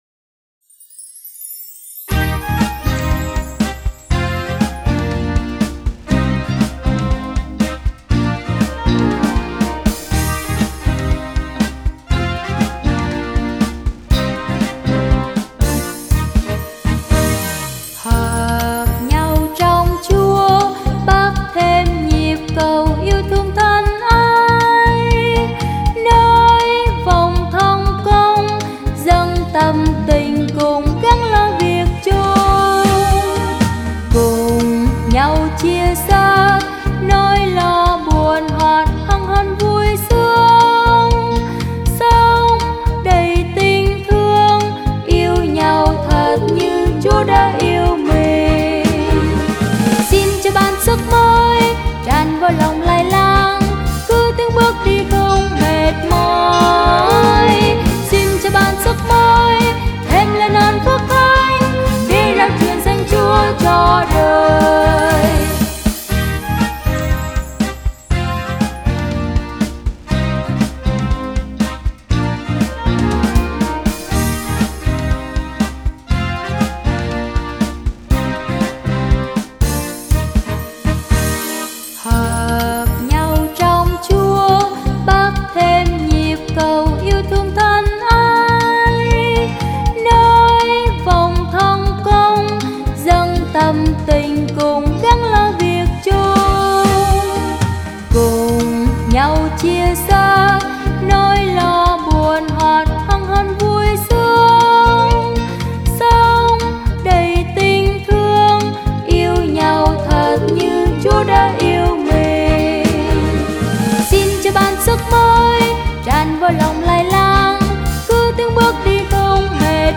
Nhạc Sáng Tác Mới